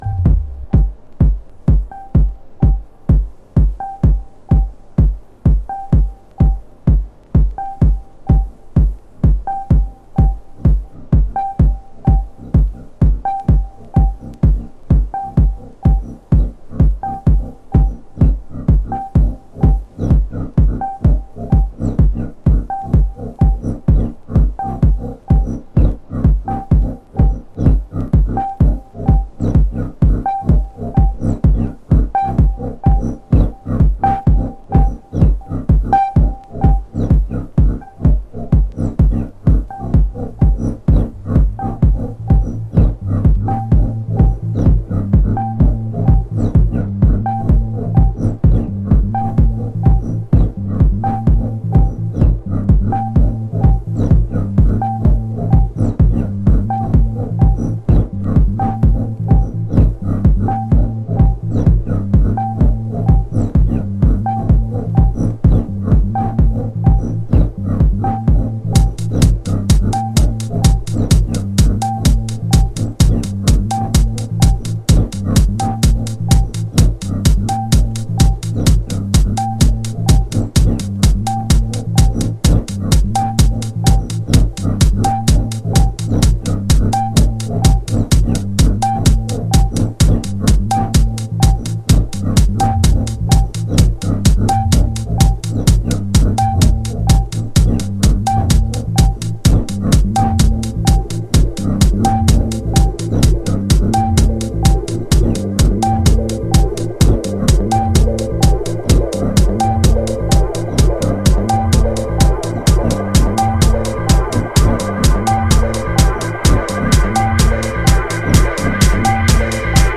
アナログに拘りぬいて90'sを昇華したマシーナリーテクノ。荒い粒子が飛び散る音像がこの方達の持ち味ですね。